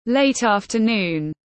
Cuối giờ chiều tiếng anh gọi là late-afternoon, phiên âm tiếng anh đọc là /leɪt ˌɑːf.təˈnuːn/
Late-afternoon /leɪt ˌɑːf.təˈnuːn/